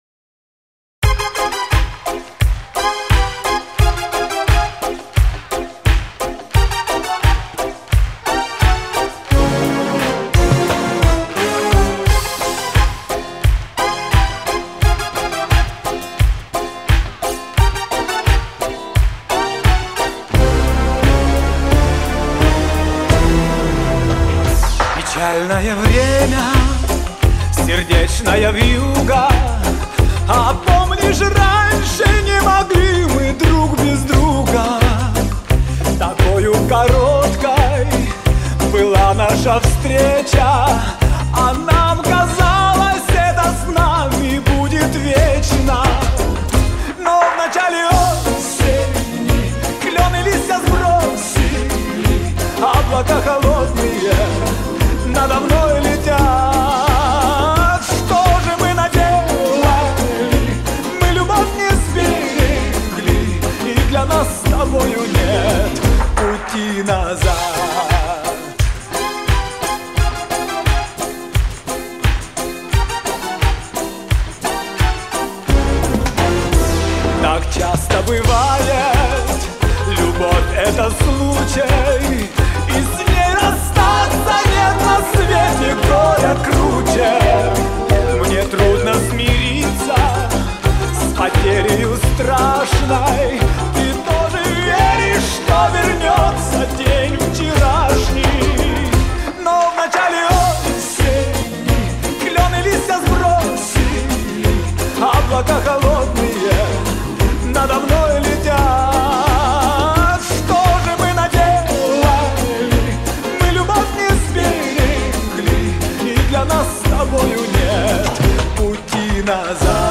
Вот исходный вариант с концерта......